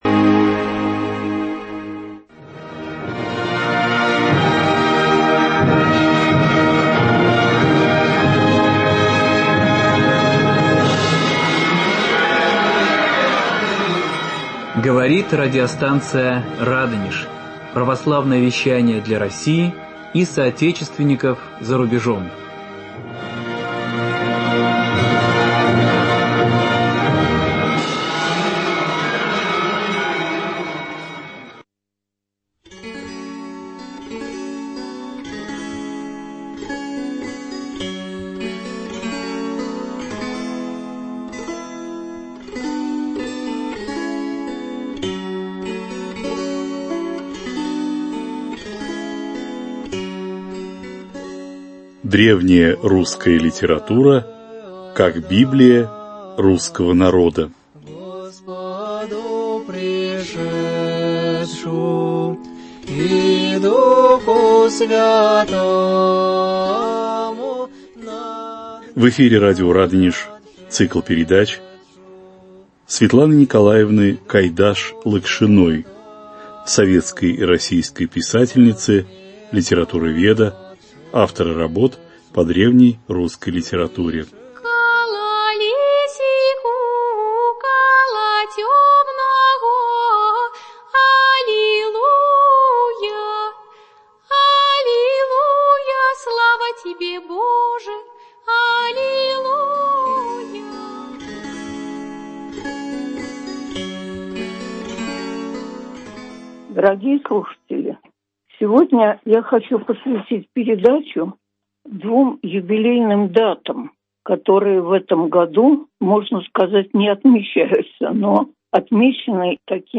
В студии радиостанции "Радонеж"